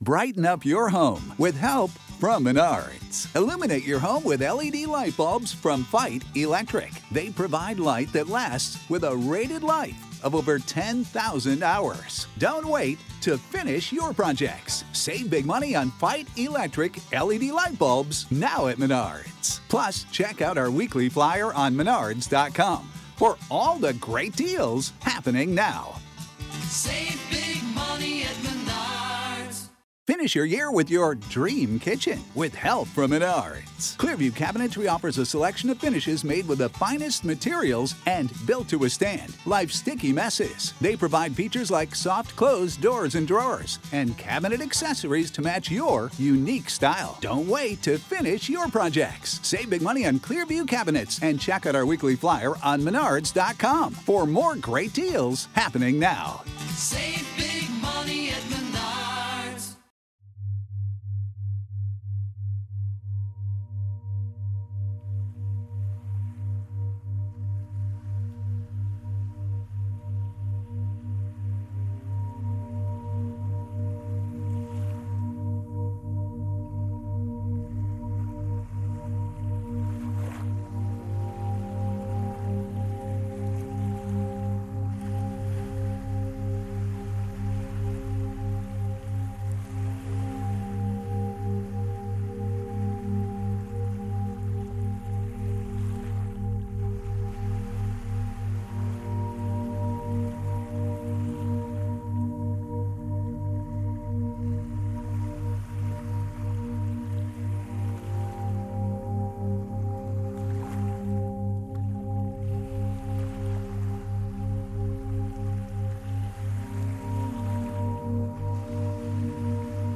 8hz - Harvest Supermoon - Alpha Binaural Beats to Decrease Stress ~ Binaural Beats Meditation for Sleep Podcast